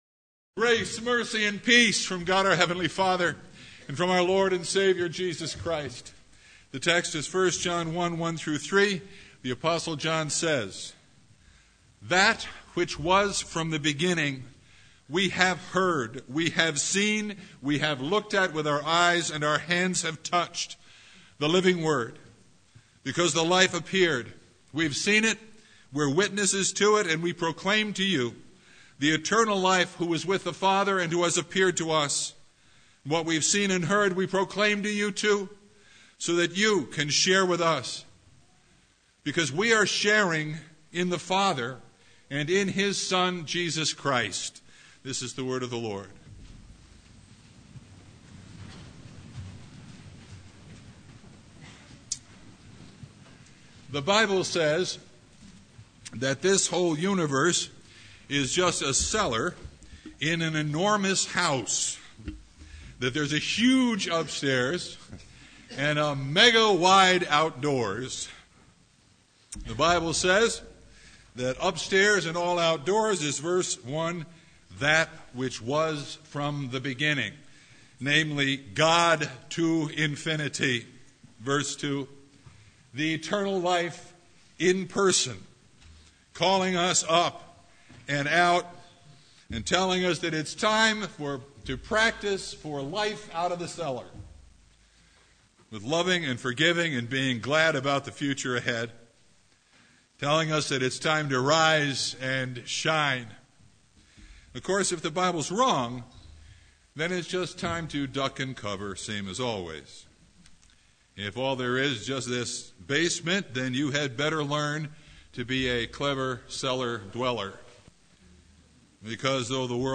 Passage: 1 John 1:1-3 Service Type: Christmas Eve
Sermon Only